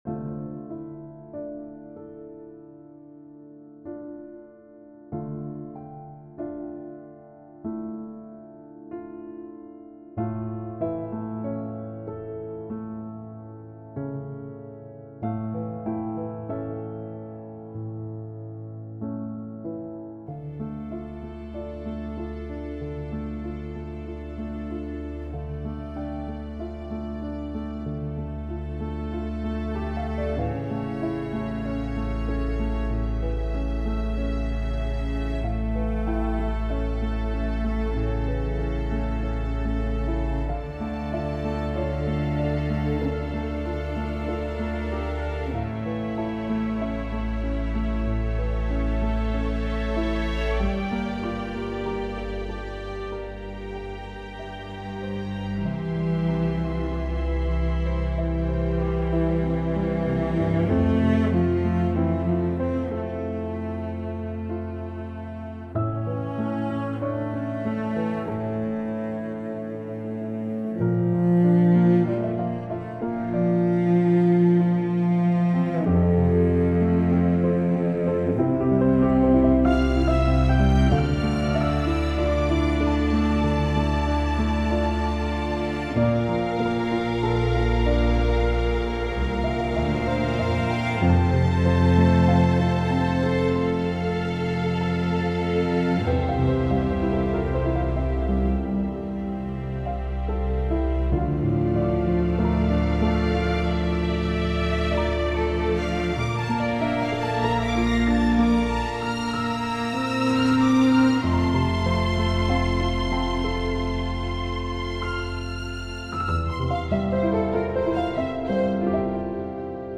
"Remembrance" | Original Score | For Piano and String Ensemble (Re-Upload...)
The key is now written in D Minor (my favorite key tbh), and I added a bridge section to it as well.